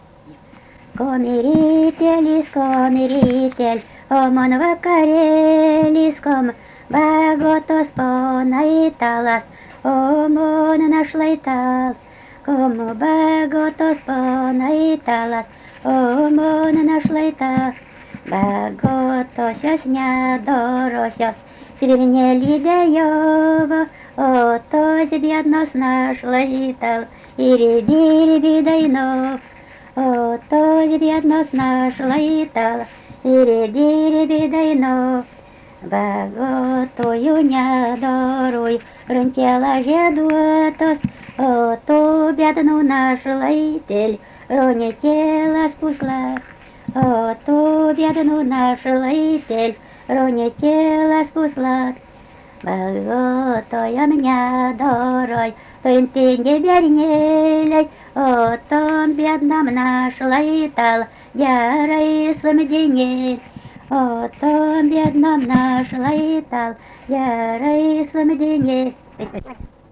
daina
Juodpėnai
vokalinis